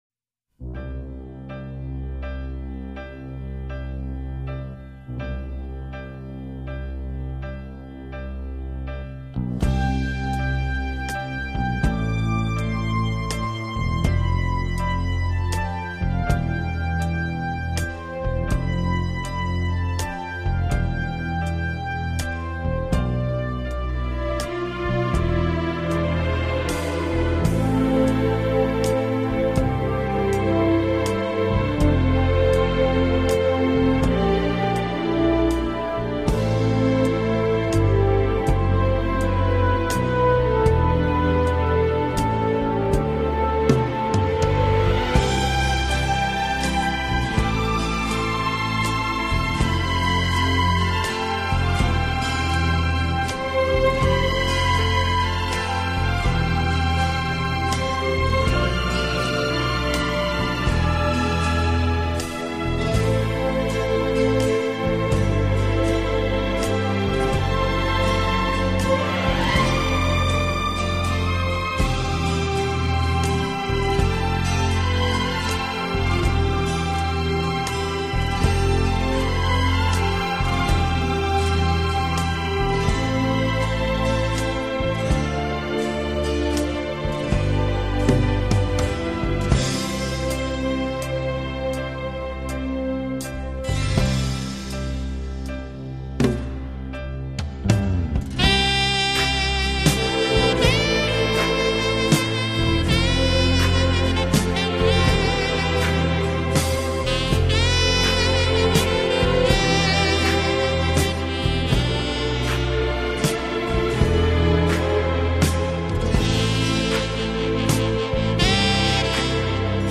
世界三大轻音乐团